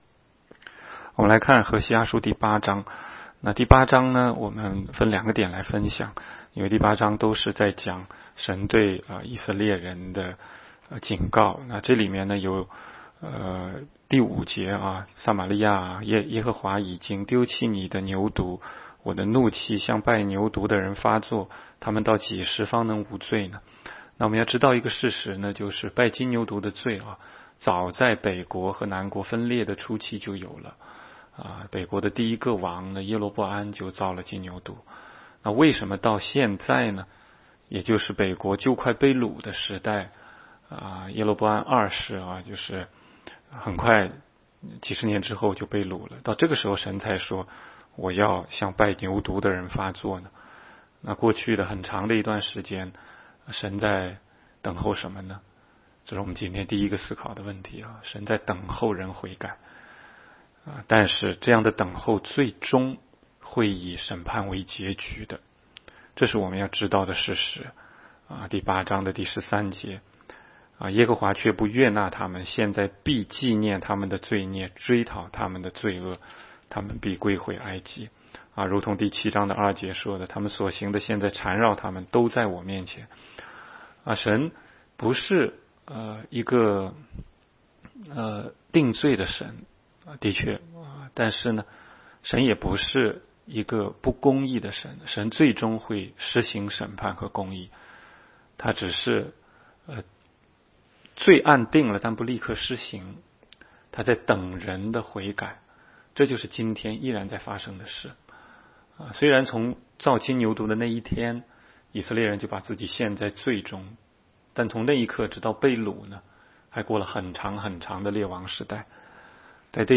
16街讲道录音 - 每日读经 -《何西阿书》8章